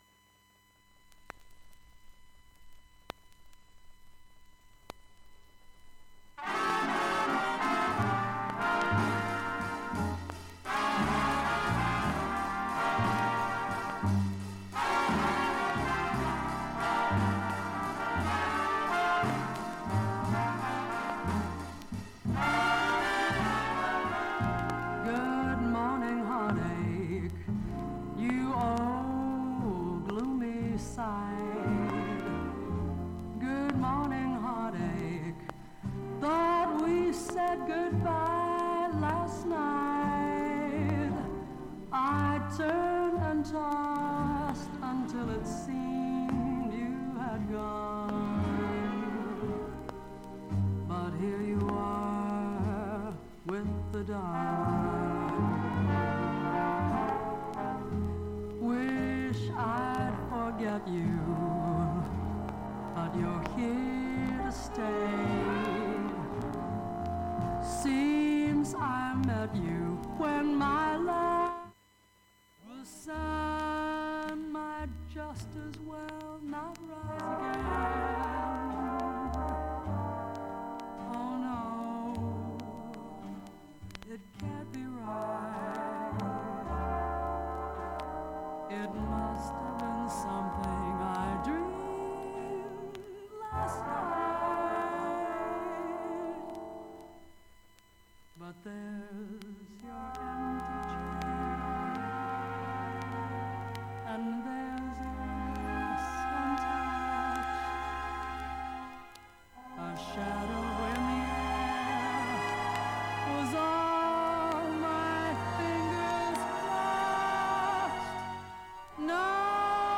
無音部もクリアで普通に音質は良好です。
B-3始めにわずかなプツが１３回出ます。
B-3中盤かすかなプツが２５回出ます。
現物の試聴（上記録音時間5分）できます。音質目安にどうぞ
◆ＵＳＡ盤オリジナルSTEREO
ハスキーで味わい深い彼女のヴォーカルを
存分に堪能出来る極上のバラード集